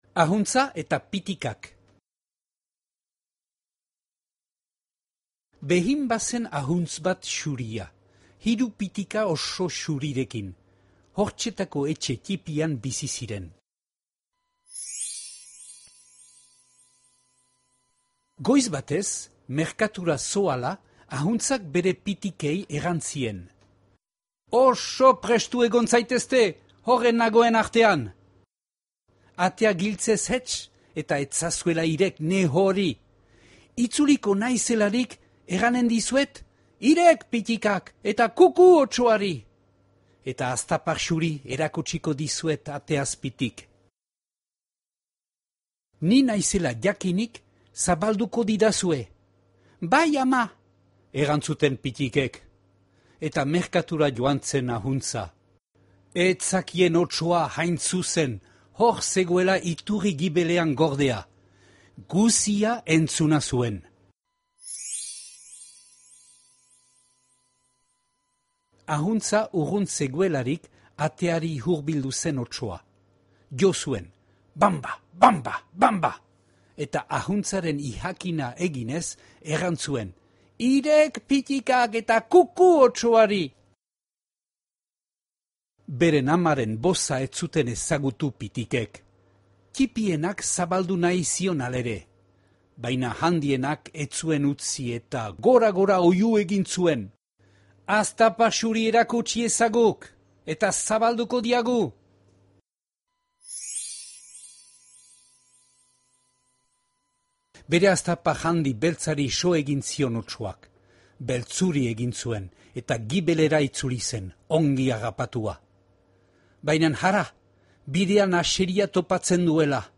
Ahuntza eta pitikak - Batuaz - ipuina entzungai
Flammarion Père Castor saileko La chèvre et les biquets albumaren itzulpena, CD batean grabatua.